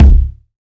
KICK.73.NEPT.wav